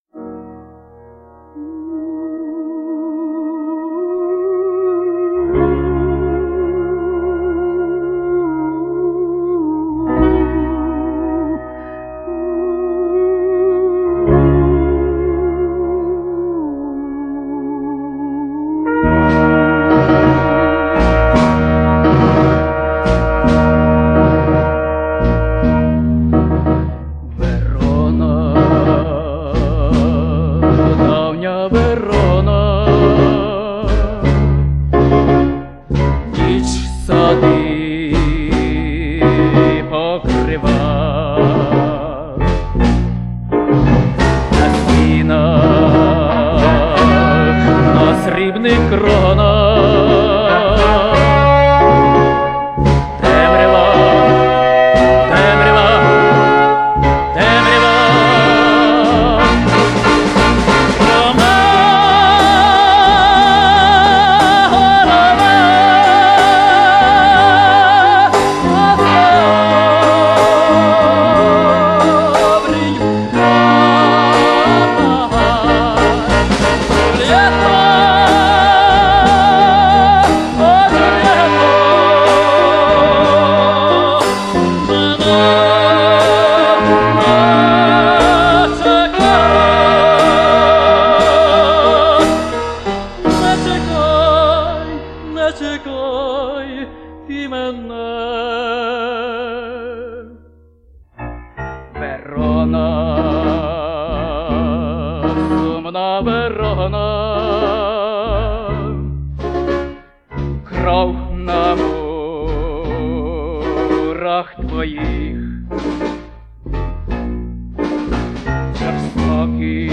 джаз-ансамбль